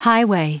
Prononciation du mot highway en anglais (fichier audio)
Prononciation du mot : highway